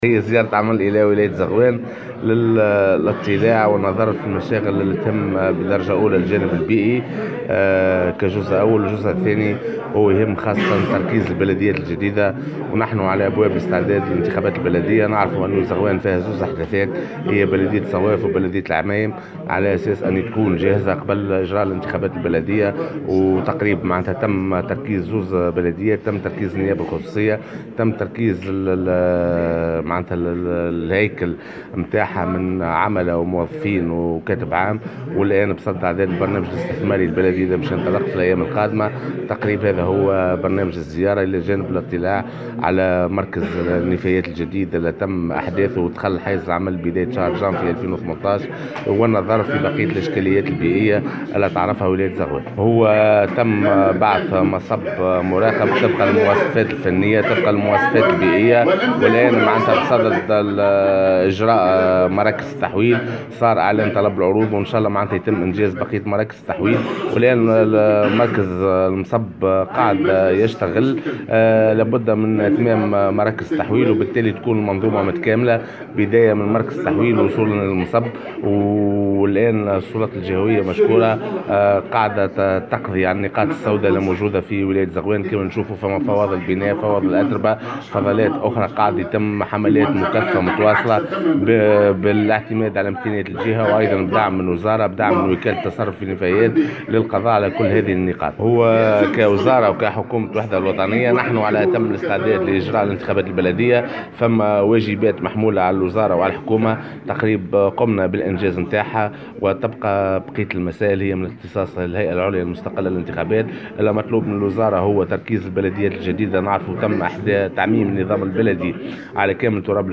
وأعلن كاتب الدولة، في تصريح لمراسلة الجوهرة أف أم، عن إتمام تركيز البلديتين المحدثتين حديثا بولاية زغوان، وهما بلديتا الصواف والعمايم، وهياكلهما المتمثلة في النيابة الخصوصية وعملتها وموظفيها وكاتبيها العامين، لينطلق عملها قبل إجراء الانتخابات البلدية في ماي المقبل.